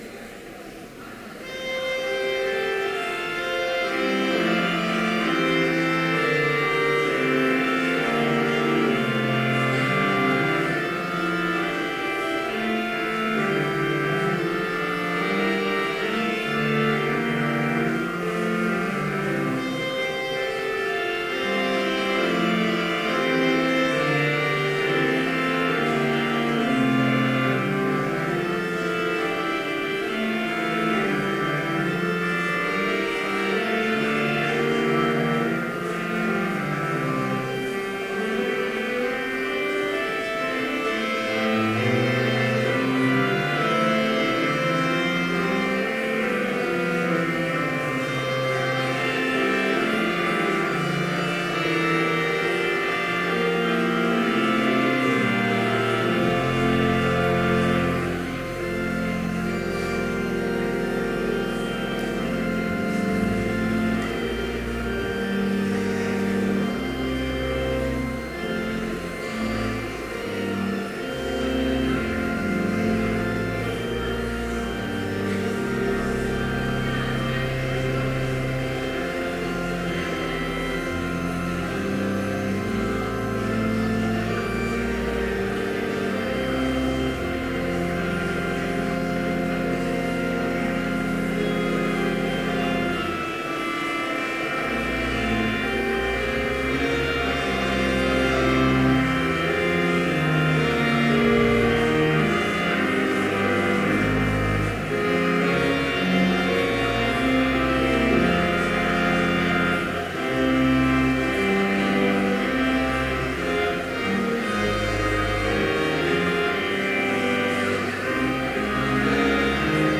Complete service audio for Chapel - August 26, 2014